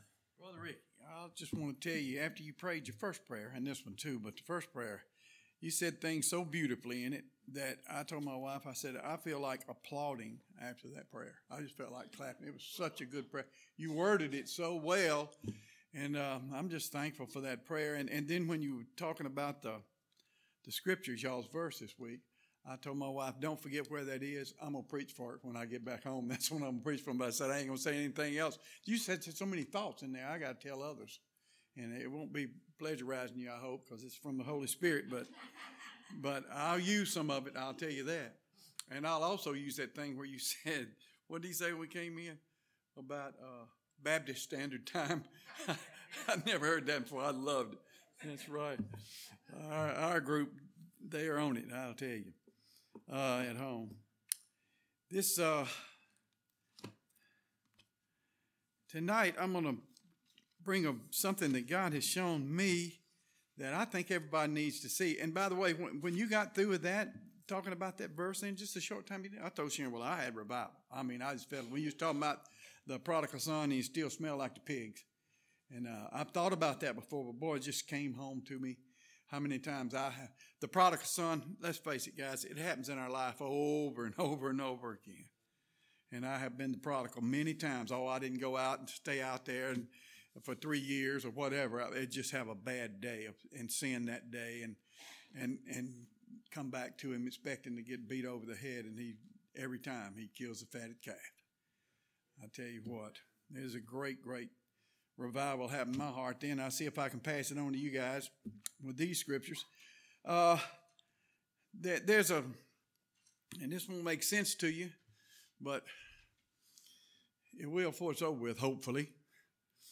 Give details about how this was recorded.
This Sunday morning sermon was recorded on January 17th, 2021.